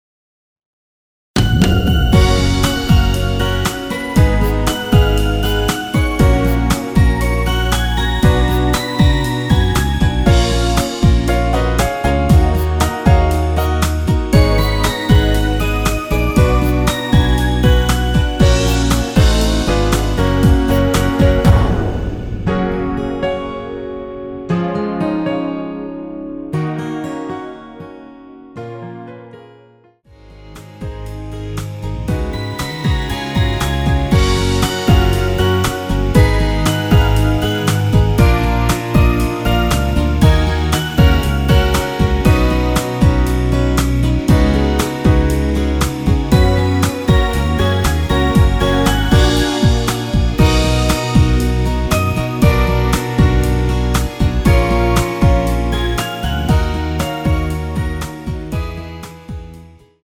원키에서(+3)올린 1절후 바로 후렴부분으로 진행되게 편곡 하였습니다.
F#
앞부분30초, 뒷부분30초씩 편집해서 올려 드리고 있습니다.
중간에 음이 끈어지고 다시 나오는 이유는